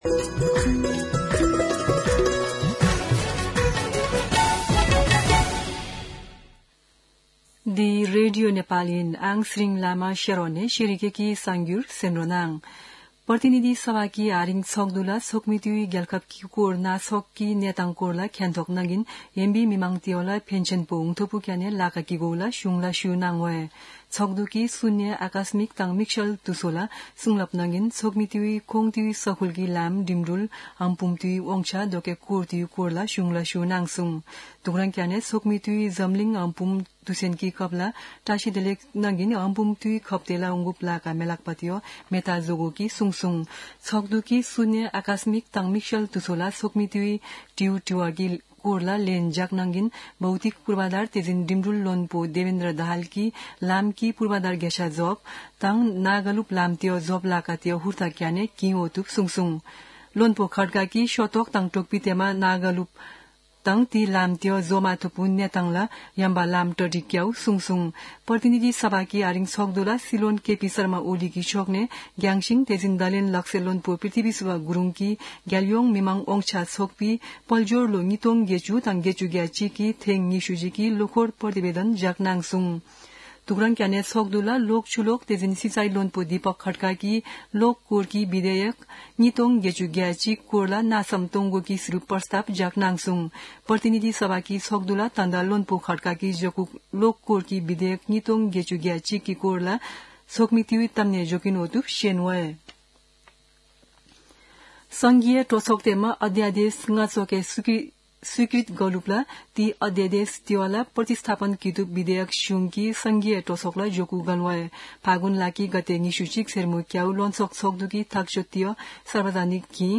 शेर्पा भाषाको समाचार : २४ फागुन , २०८१
Sherpa-News-23.mp3